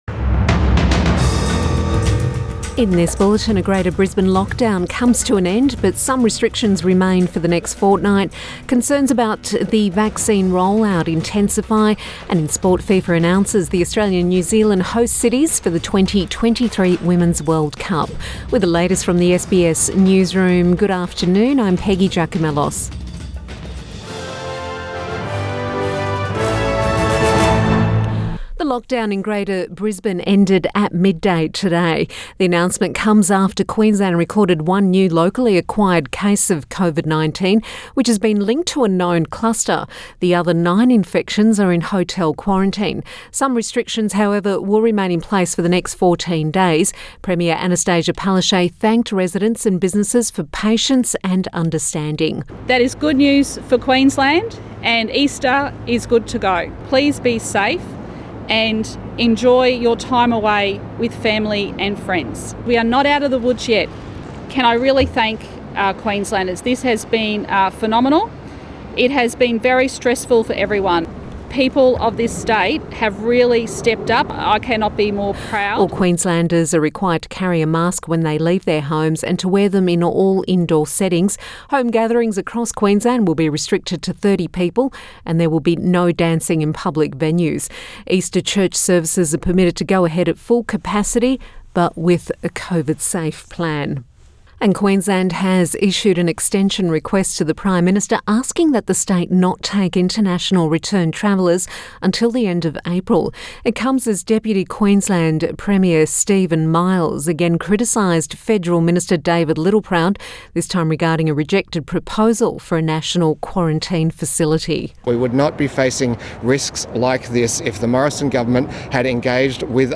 Midday bulletin 1 April 2021